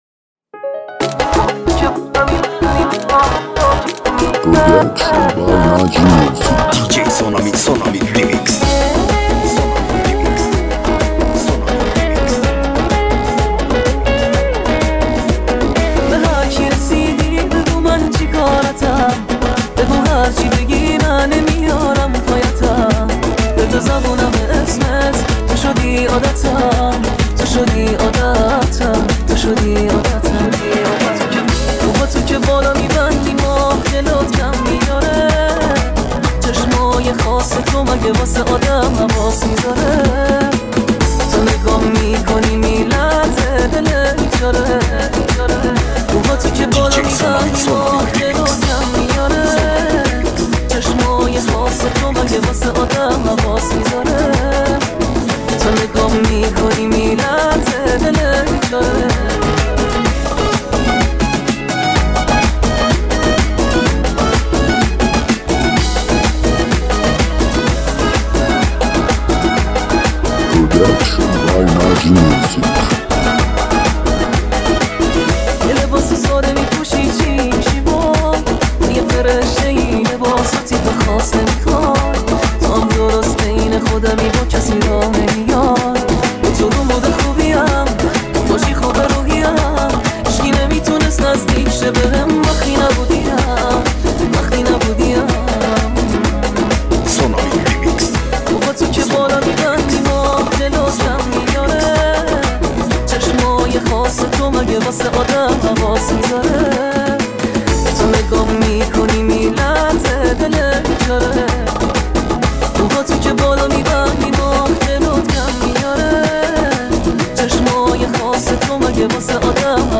دانلود ریمیکس شاد تریبال رقصی